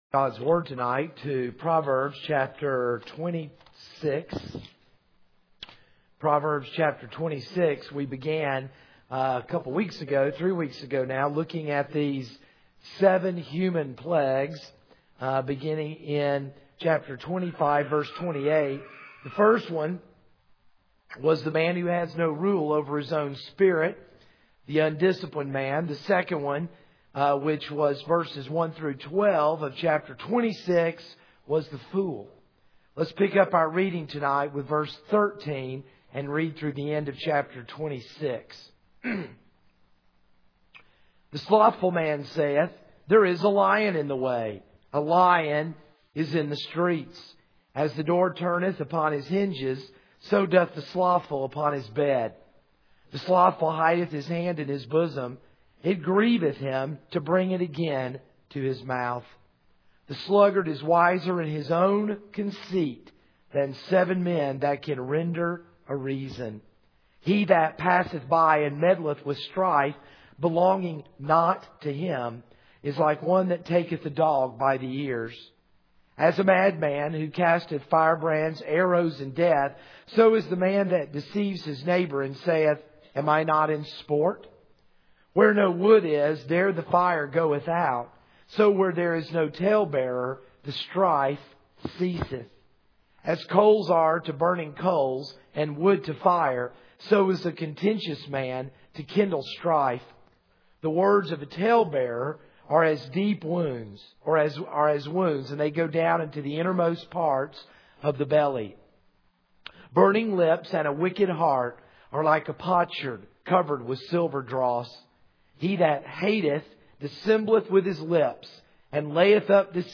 This is a sermon on Proverbs 26:13-28.